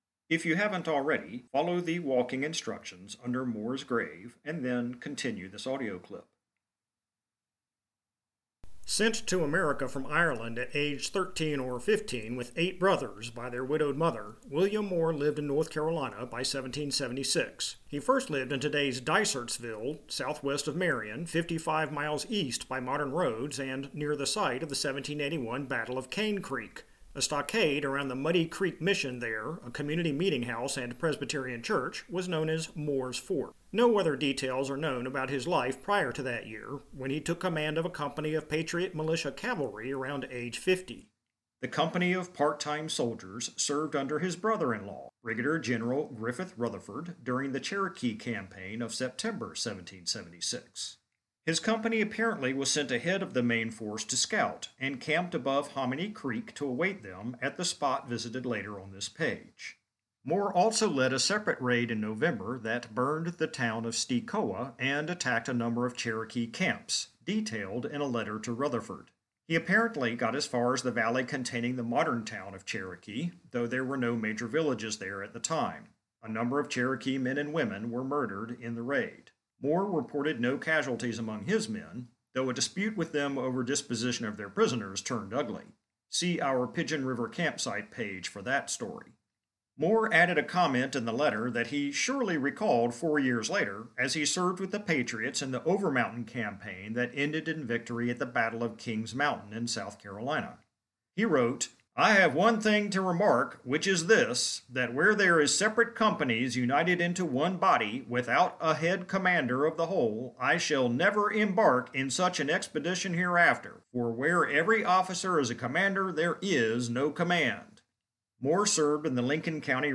Button for audio tourMoore’s Grave